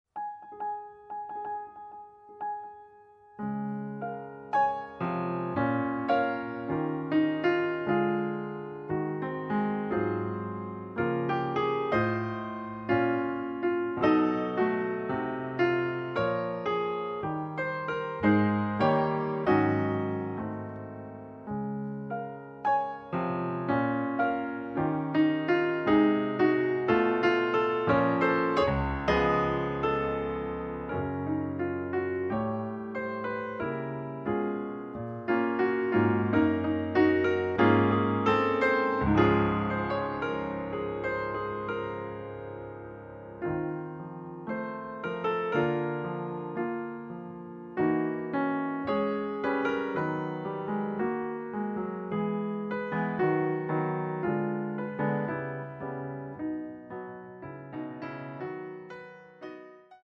accompaniment excerpt